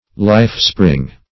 (l[imac]f"spr[i^]ng`)